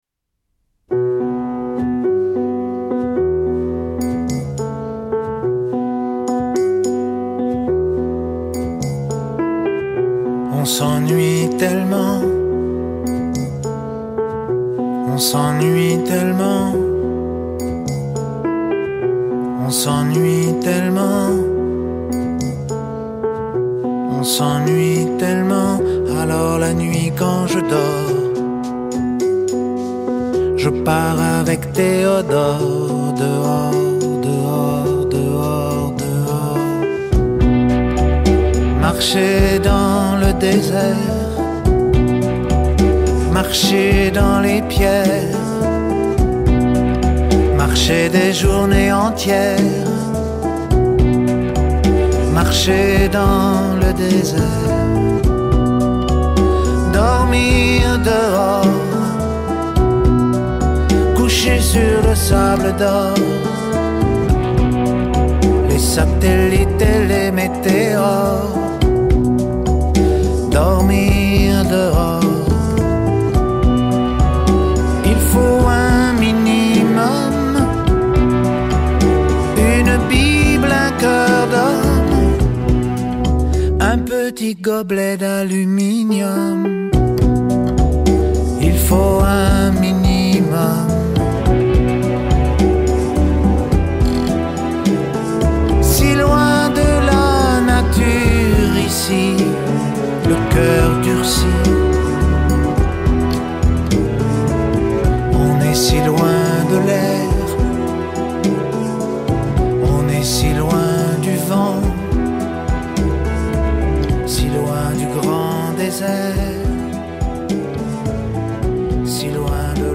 tonalité SI mineur